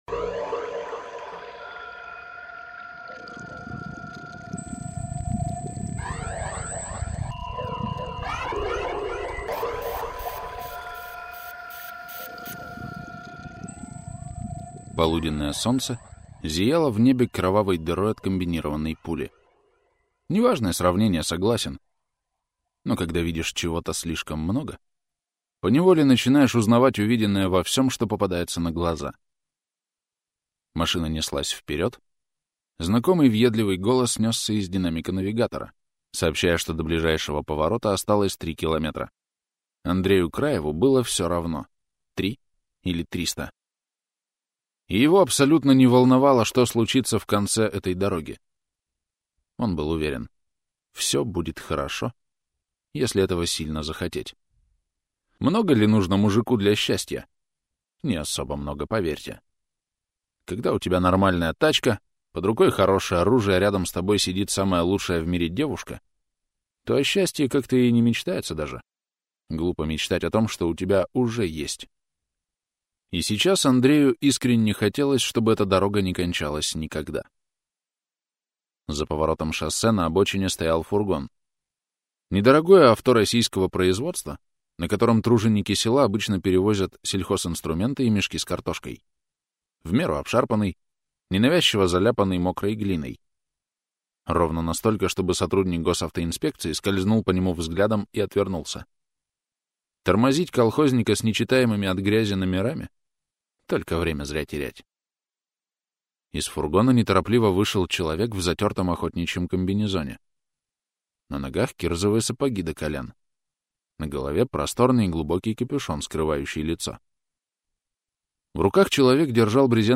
Прослушать фрагмент аудиокниги Закон охотника Дмитрий Силлов Произведений: 13 Скачать бесплатно книгу Скачать в MP3 Вы скачиваете фрагмент книги, предоставленный издательством